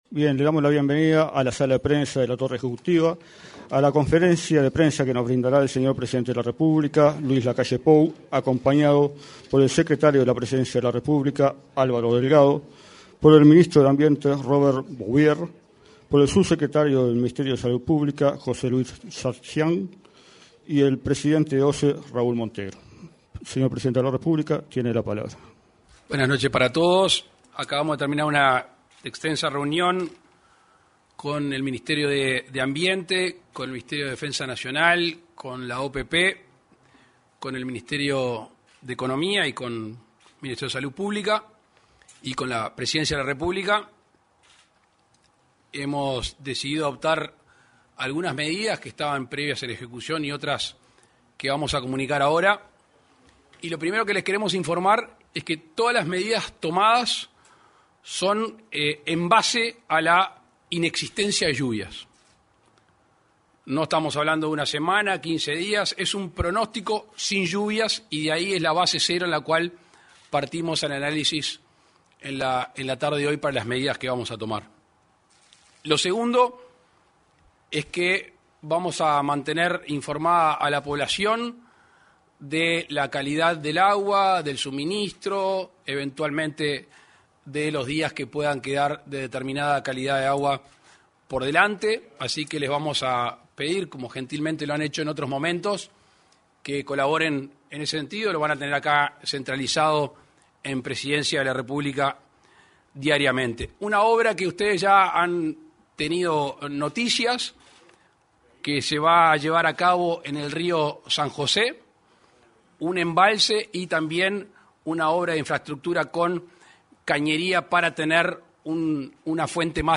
Conferencia de prensa sobre situación hídrica en el área metropolitana
Este lunes 19 de junio, el presidente de la República, Luis Lacalle Pou, se expresó en conferencia de prensa acerca de la situación hídrica en el área